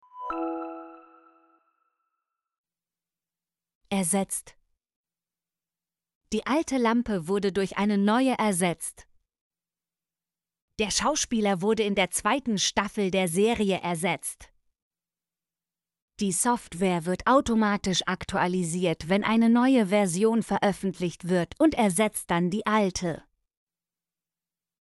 ersetzt - Example Sentences & Pronunciation, German Frequency List